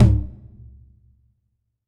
9MIDTOM1.wav